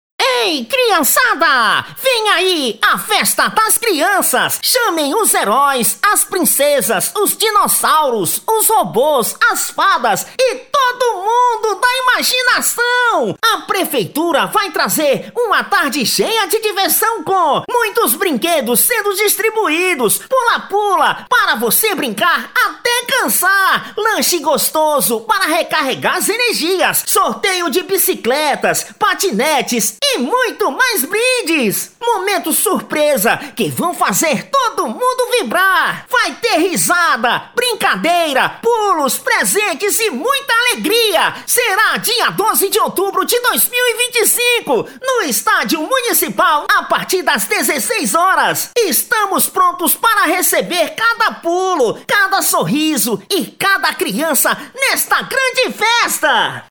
PALHAÇO: